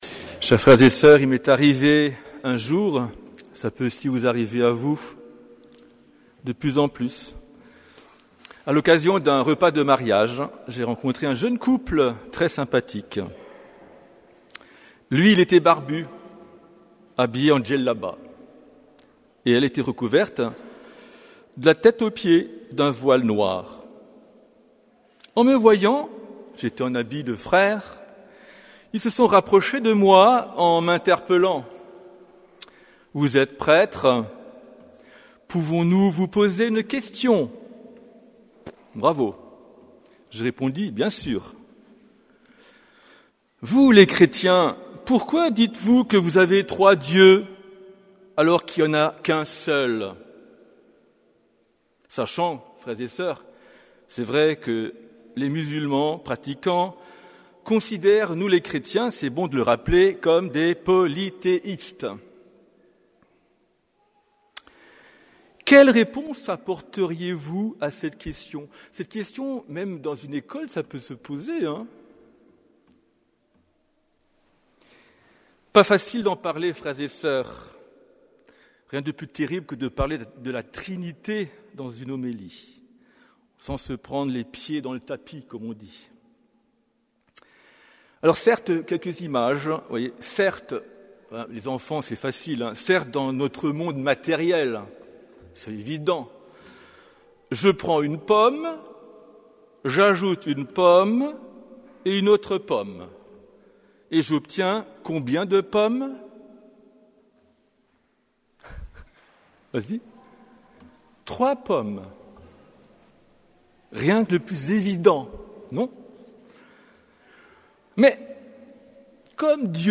Homélie de la solennité de la Sainte Trinité